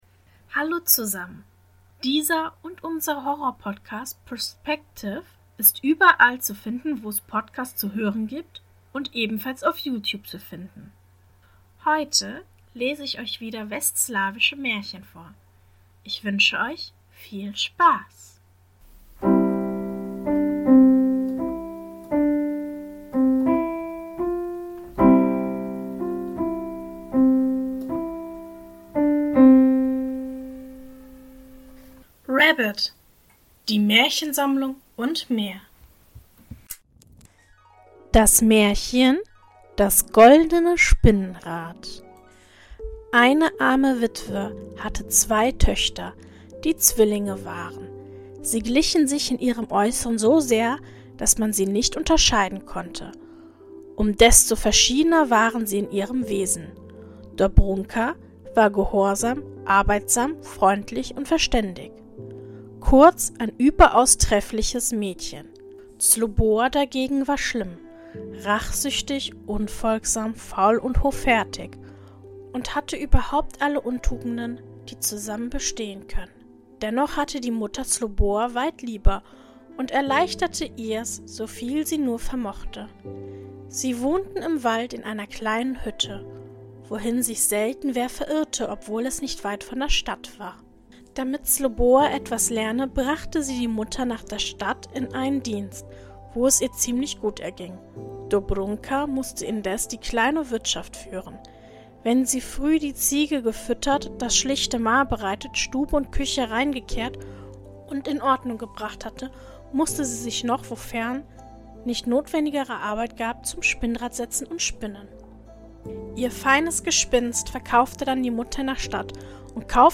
In der heutigen Folge lese ich Folgendes vor: 1. Das goldene Spinnrad Mehr